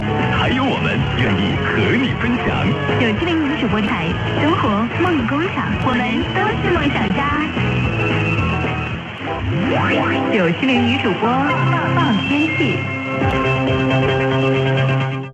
Tilalle tuli tämä 97.0 Hostess Radio eli kiinaksi 9-7-0 (jiu-qi-ling) Nǚ zhǔbō, joka nimensä mukaisesti käyttää vain naisjuontajia.